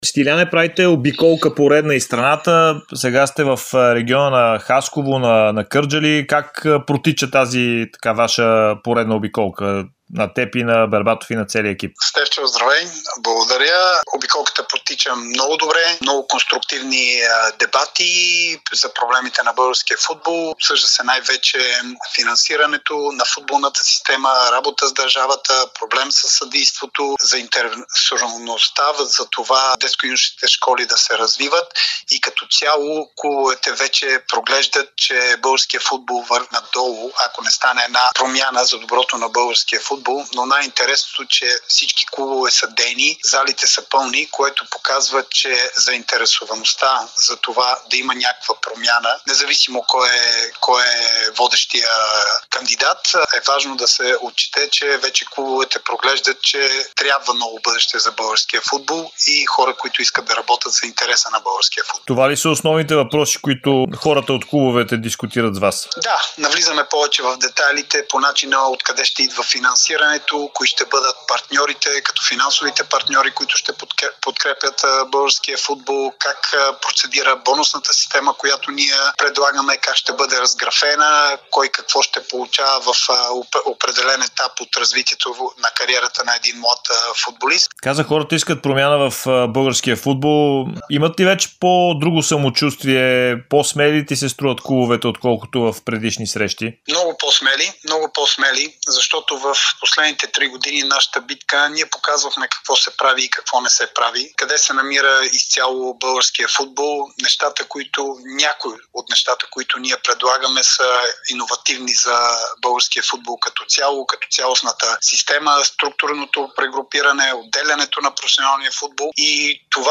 Рекордьорът по мачове с националната фланелка на България Стилиян Петров даде ексклузивно интервю пред Dsport и Дарик радио, в което говори за обиколката, която екипът на Димитър Бербатов прави из българските градове, за да запознава местните футболни клубове със своята програма за развитието на българския футбол преди изборния Конгрес на 15 март.